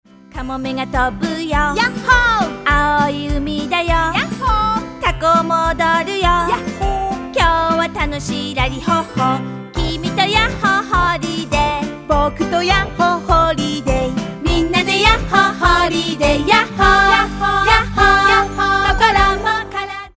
ダンス